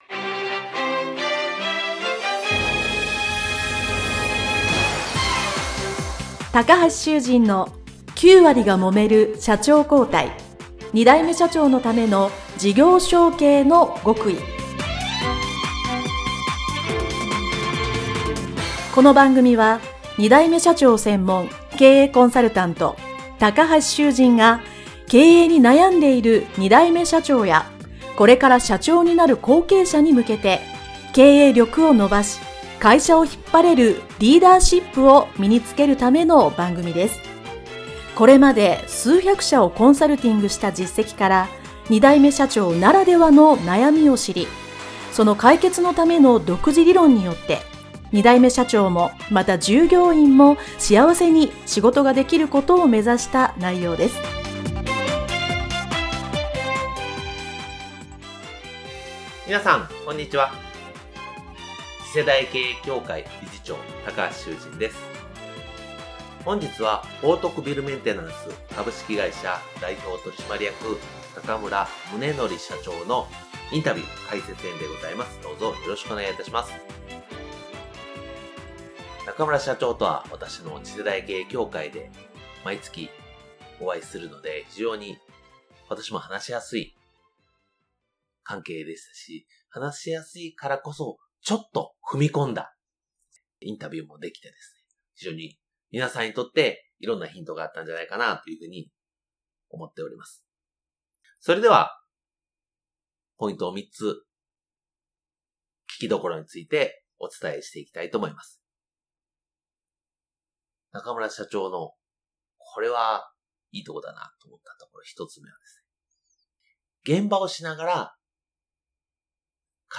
インタビュー解説編